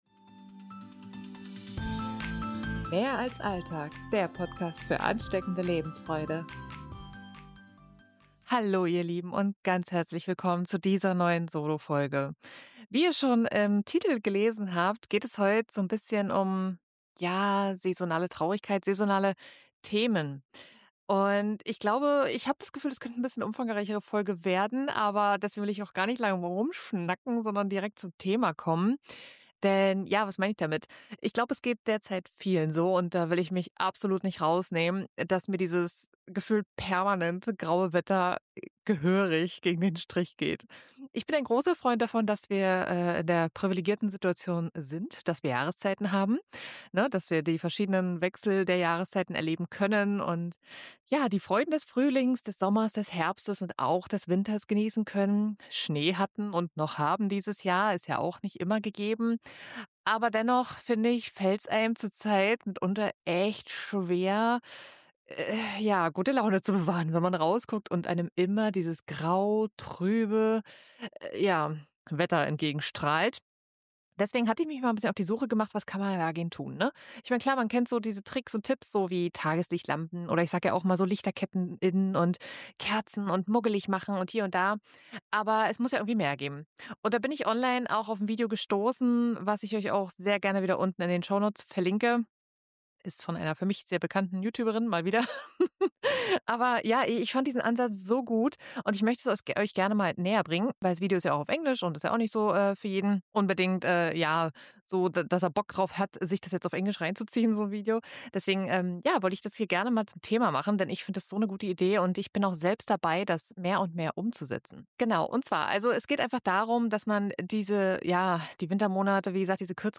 In dieser Solofolge spreche ich über saisonale Traurigkeit in den Wintermonaten und wie du dem entgegenwirkst: Nicht durch “Überstehen”, sondern durch bewusstes Zelebrieren jeder Jahreszeit! Ich teile ein tolles Konzept mit euch, wie man das ganze Jahr über in saisonale Themen aufteilen kann – von "Fresh Start" im Januar über "Selbstliebe" im Februar bis hin zur Weihnachtszeit.